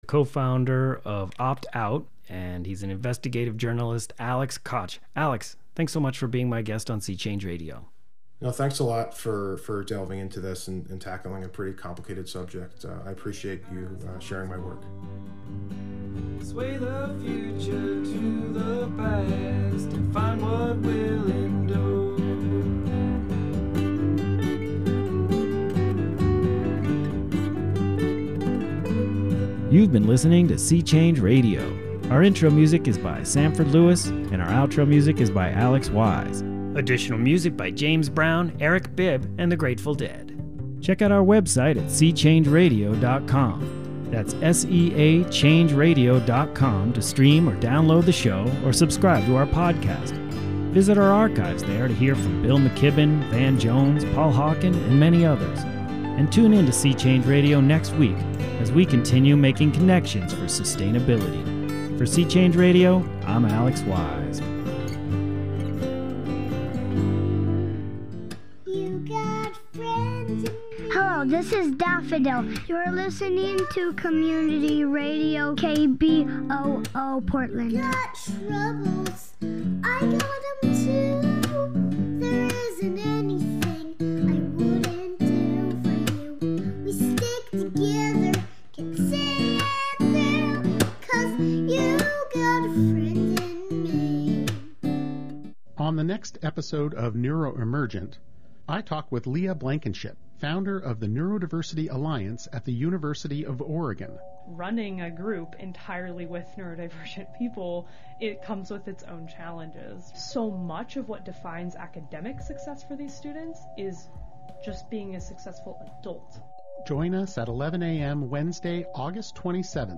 In a sentence: A panel broadcast reviewing the latest films and shows from the multiplex to the arthouse and beyond.